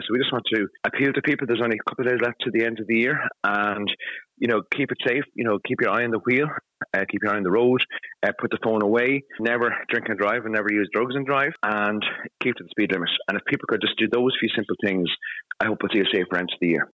Junior Transport Minister James Lawless says we know what to do to ‘keep it safe’: